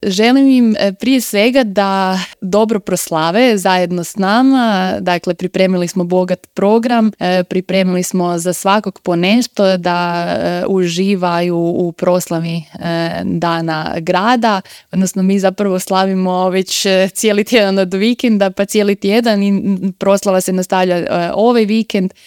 ZAGREB - U intervjuu Media servisa povodom rođendana grada Samobora gostovala je gradonačelnica Petra Škrobot.